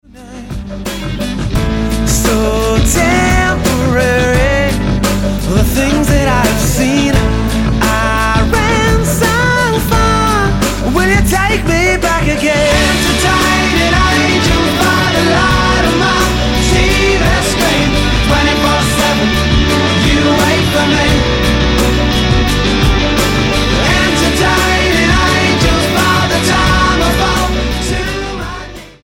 STYLE: Rock
Timelessly catchy pop-rock.